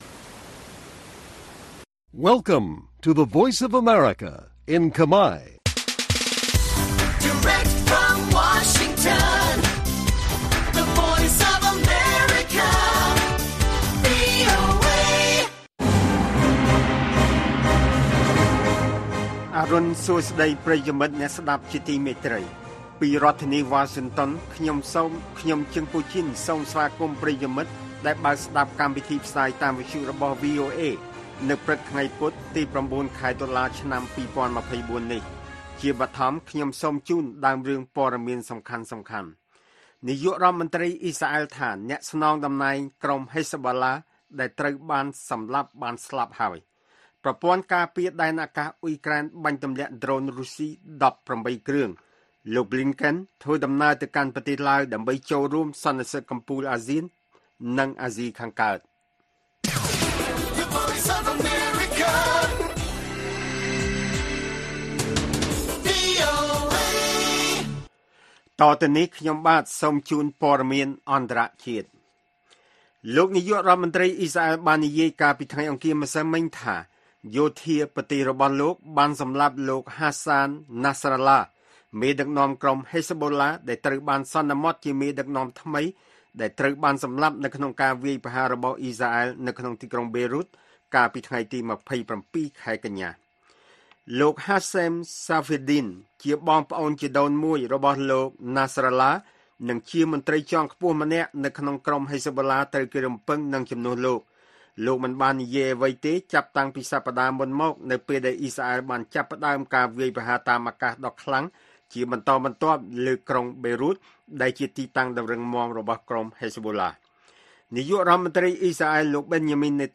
ព័ត៌មានពេលព្រឹក៩ តុលា៖ នាយករដ្ឋមន្ត្រីអ៊ីស្រាអែលថាអ្នកស្នងតំណែងក្រុម Hezbollah ដែលត្រូវបានសម្លាប់បានស្លាប់